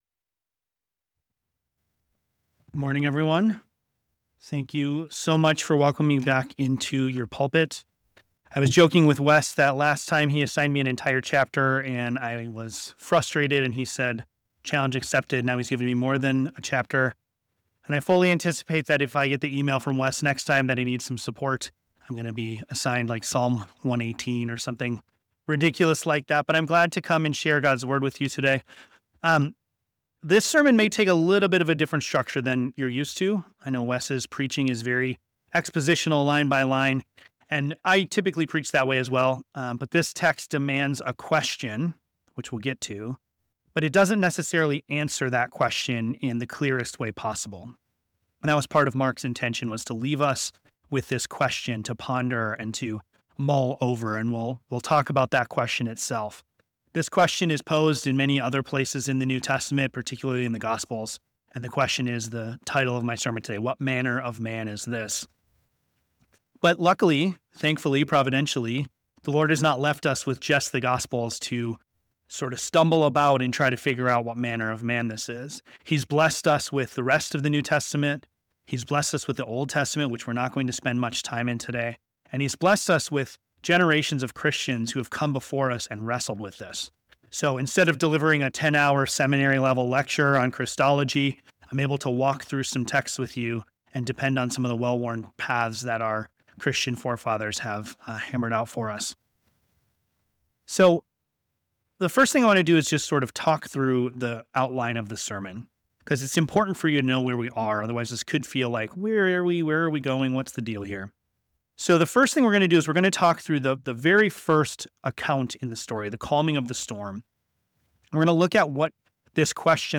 The sermon also delves into the historical development of Christological doctrines, referencing the early church councils, such as Nicaea and Chalcedon, which defended the biblical teaching that Jesus is one person with two natures—united without confusion, separation, or division.